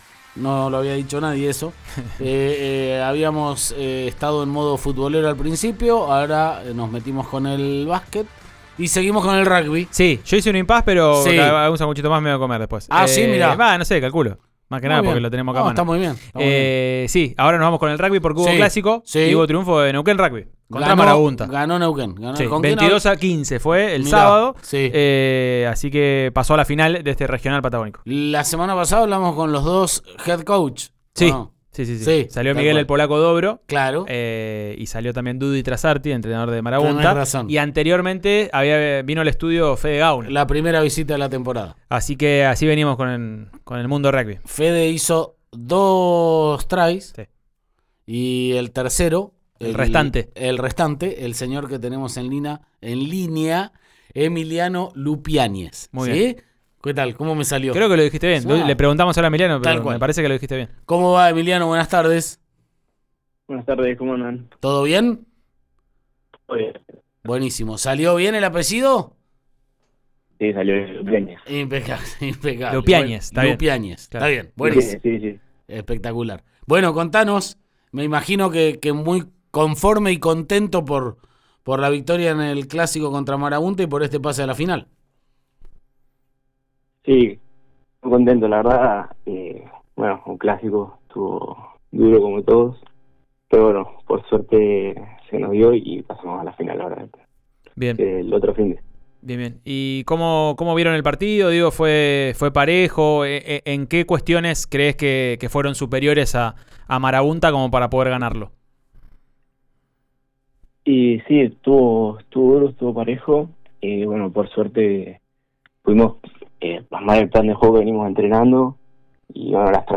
en diálogo con «Subite al Podio» de Río Negro Radio